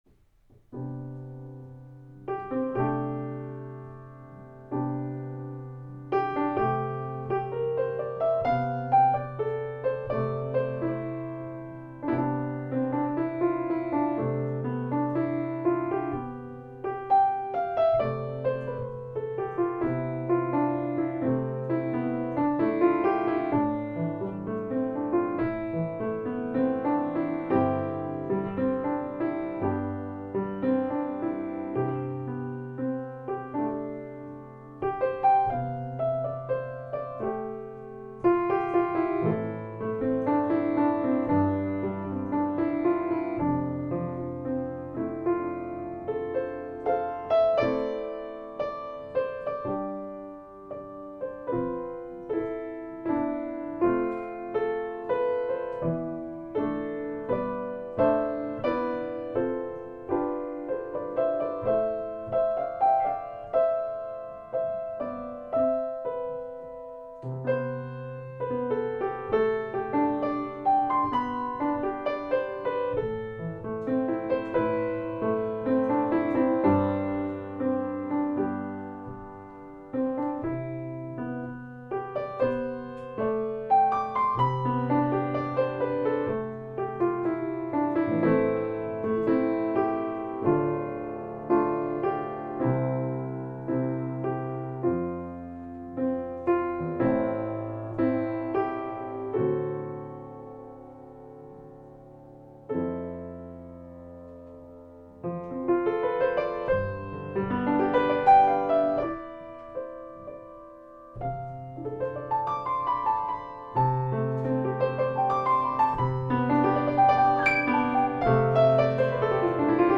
Abgelegt unter: Piano